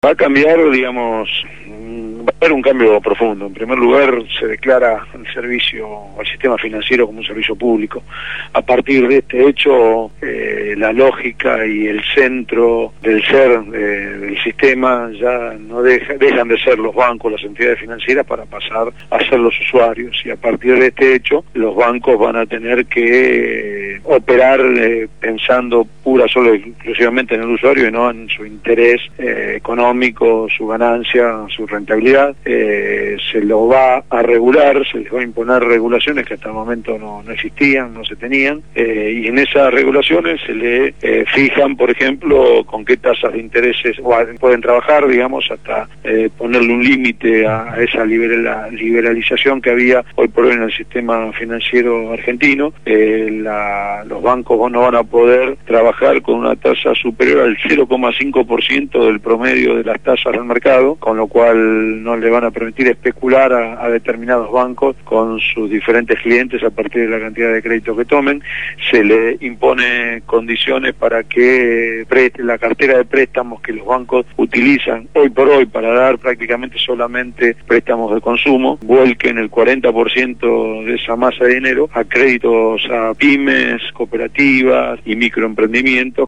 Ariel Basteiro presentó la nueva Ley de Entidades Financieras en Radio Gráfica